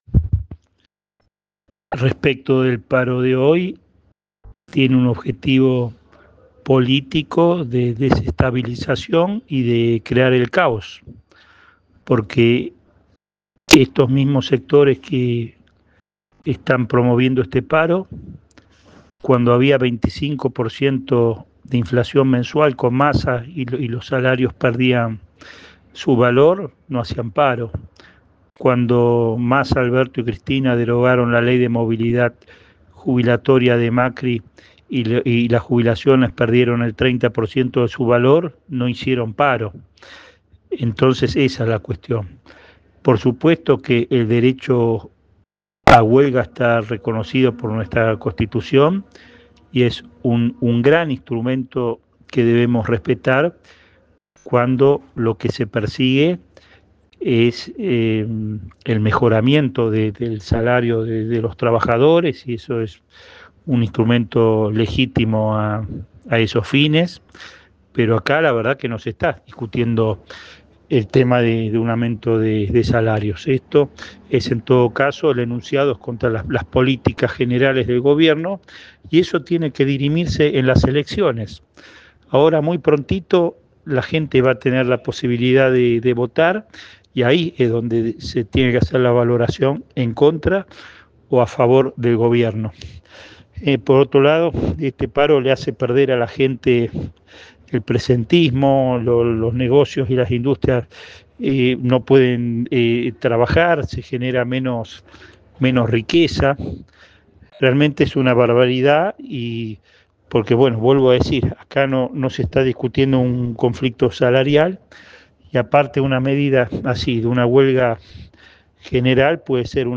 Desde Argentina Política, conversamos con diferentes actores afines al gobierno Nacional.